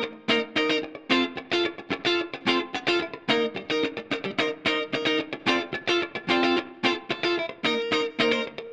30 Guitar PT3.wav